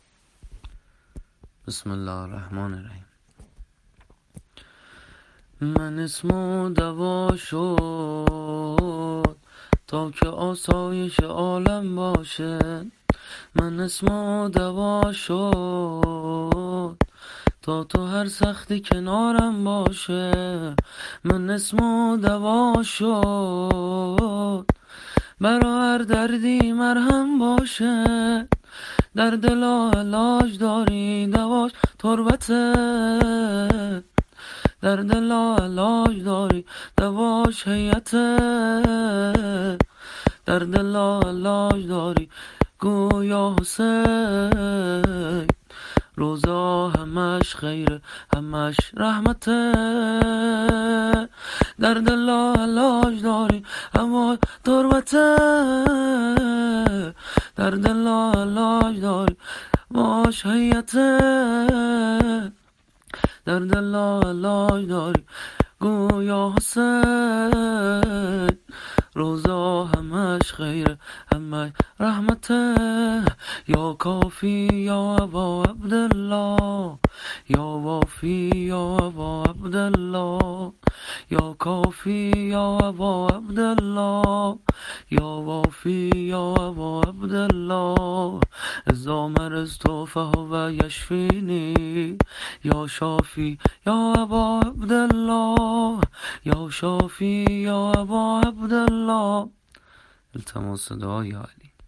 شور ، هروله روضه ي امام حسين سلام الله عليه -(مَن اِسمُه دوا شد)